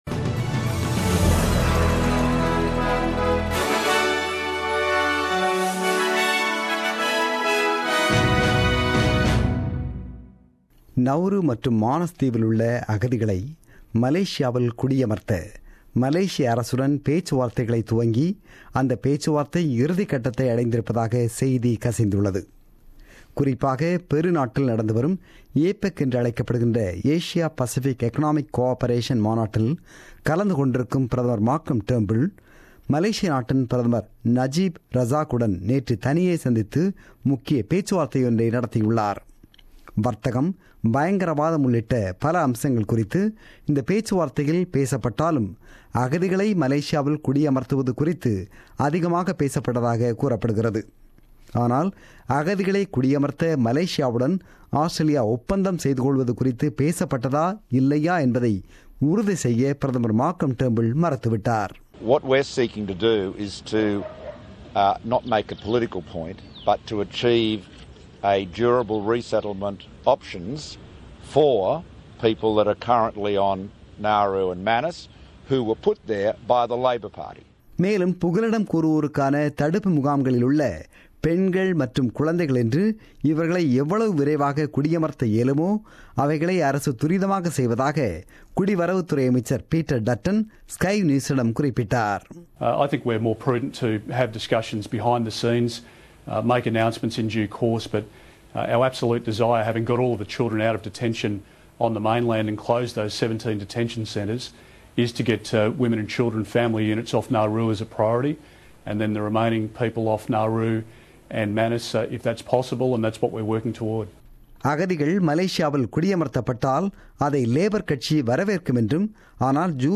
The news bulletin broadcasted on 20 Nov 2016 at 8pm.